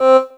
10SYNT01  -R.wav